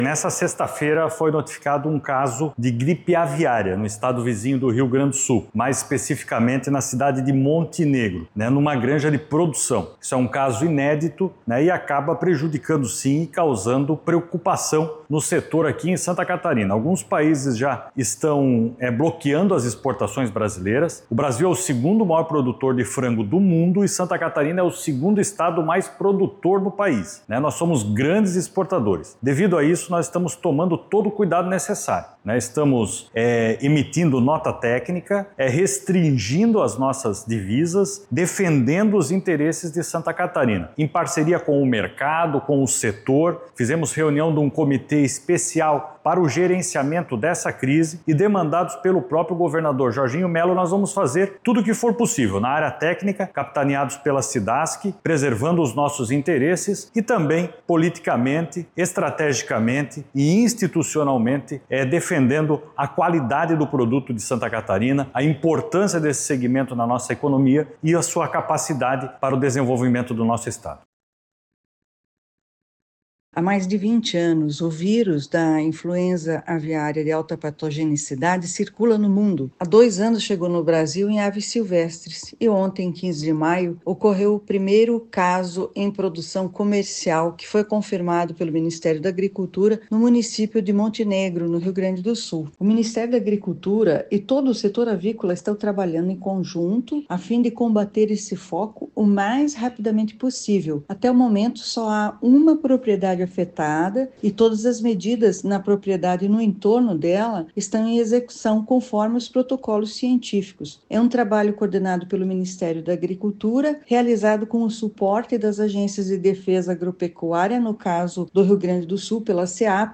O secretário de Estado da Agricultura e Pecuária, Carlos Chiodini, ressalta que a pasta está vigilante e reforçando todas as medidas para impedir a entrada dessa doença em Santa Catarina:
Os produtores devem reforçar as medidas de biosseguridade e proibir visitas de pessoas alheias ao sistema de produção, além de ficar atentos a questões como taxa de mortalidade das aves, entre outras, como explica a presidente da Cidasc, Celles Regina de Matos: